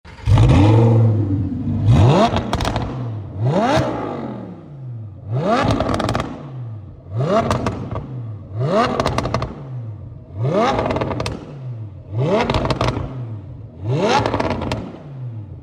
• One of the very last Audi R8 V10’s produced without an GPF/OPF Filter muffling the sound of the exhaust note
Listen to the sweet V10 Symphony
audi-r8-v10-plus-performance-parts-edition-Audi-exclusive-misano-red_Revs_VAJ.mp3